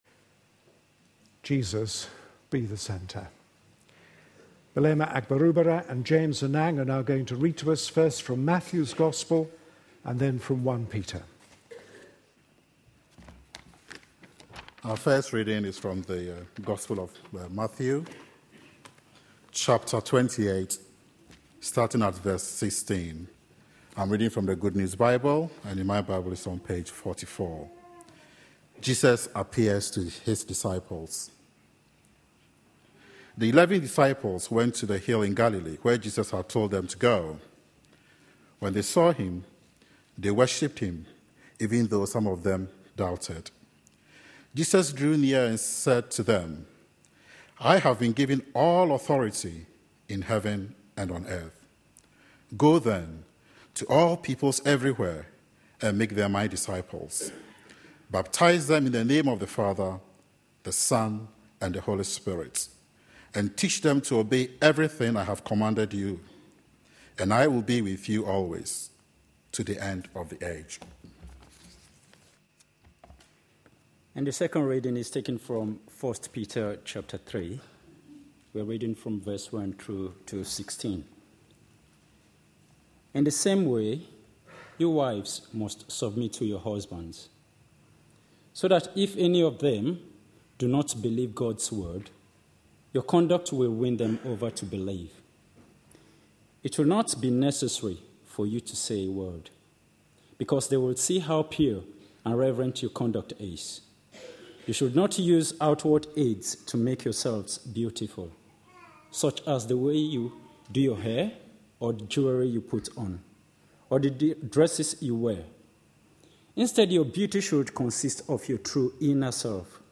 A sermon preached on 28th October, 2012, as part of our The Message of Peter for Today series.